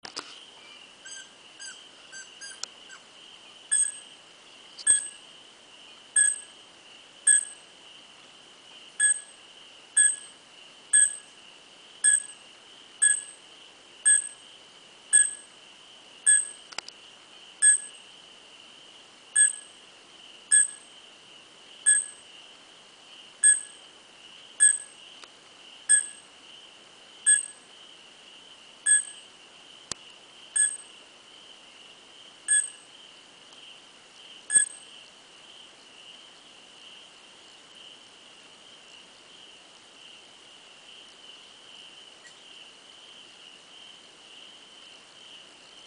Wahlberg’s Epauletted Fruit Bat
The male epauletted fruit bat is known for its persistent pinging call emitted as it hangs in a tree, hoping to attract a willing female or perhaps to declare its territory.
Pafuri-River-Camp-epo-fruit-bat.mp3